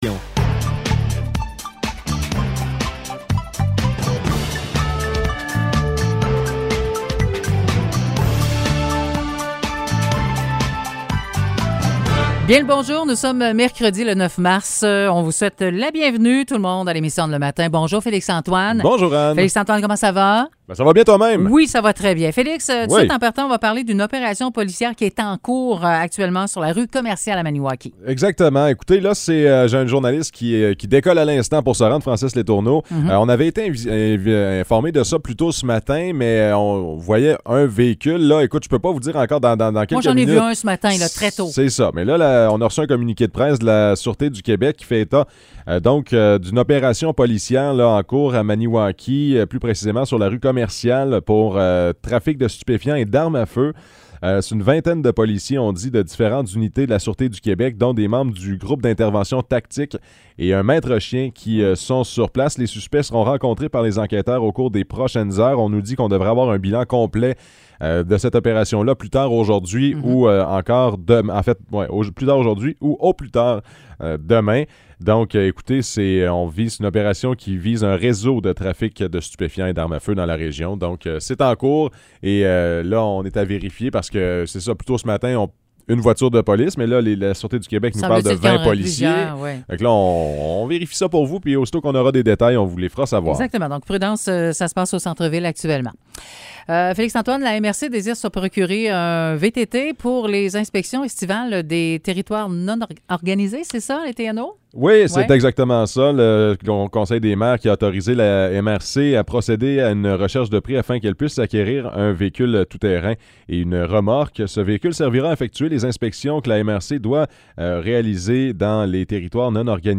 Nouvelles locales - 9 mars 2022 - 9 h